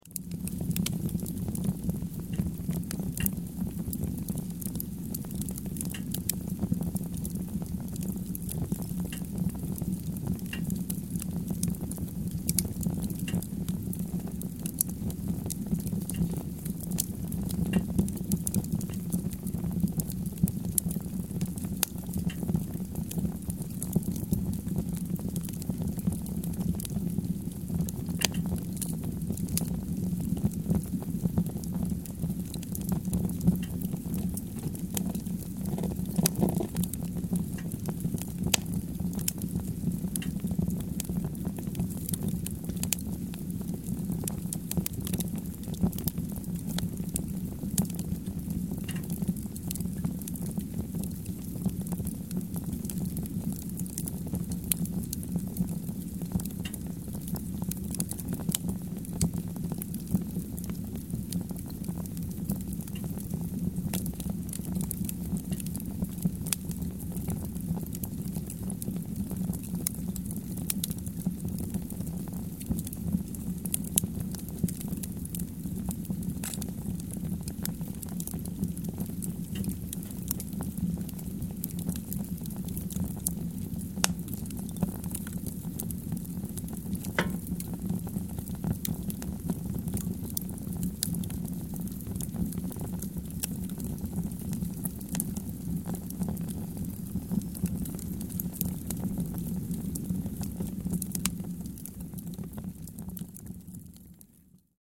Fire_sounds.ogg